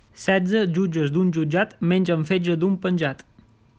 Qui la pronuncia: